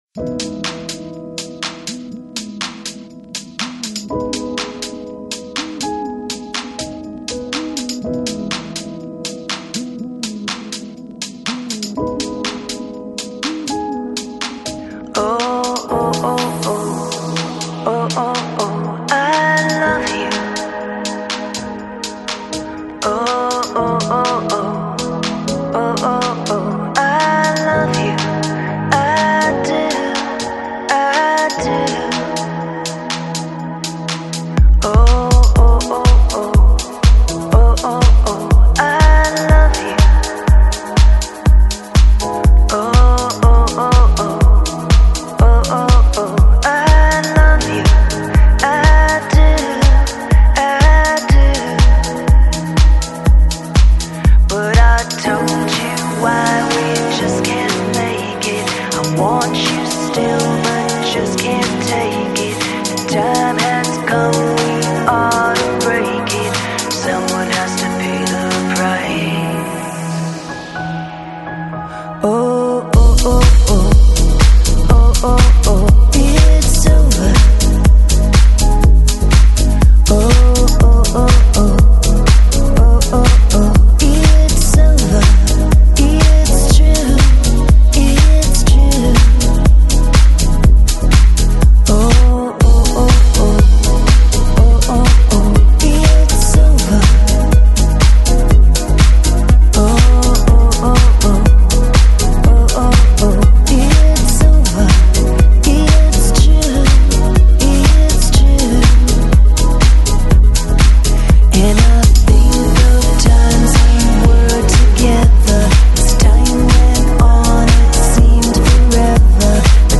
Lounge, Downtempo, Bossa Nova, Pop